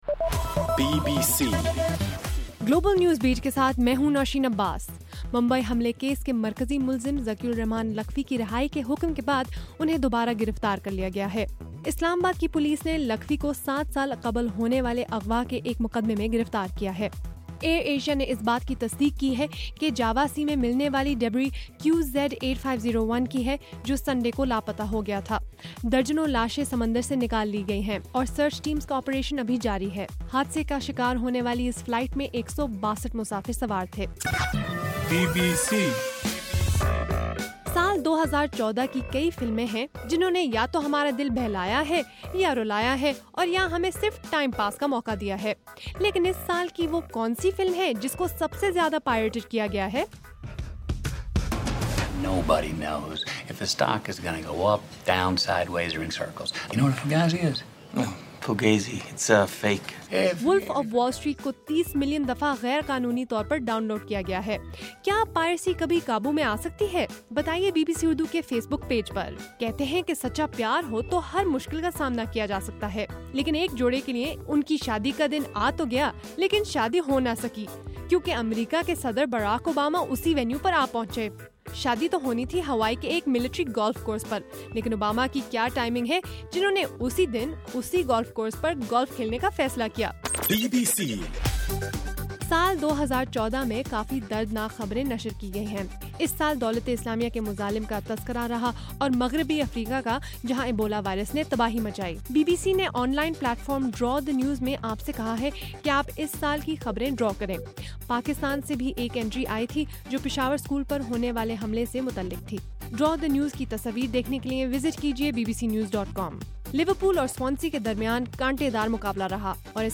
دسمبر 30: رات 8 بجے کا گلوبل نیوز بیٹ بُلیٹن